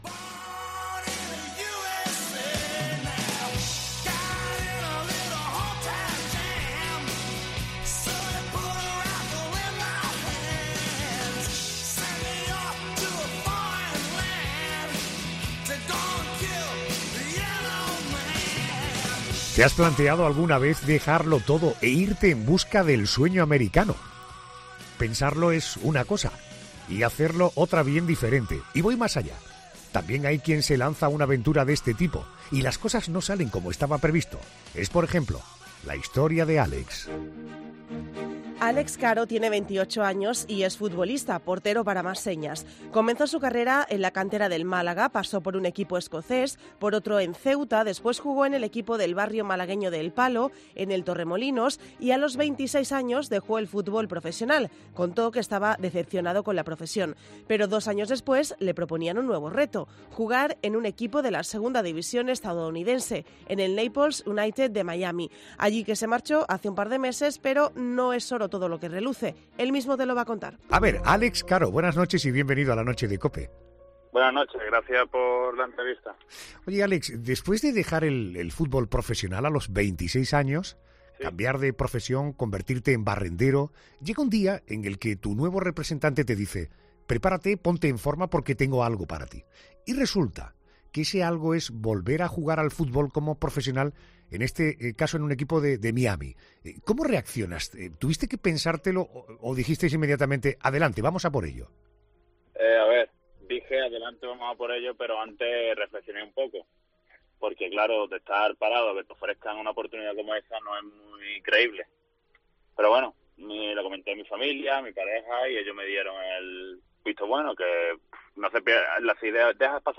una entrevista en 'La Noche de COPE'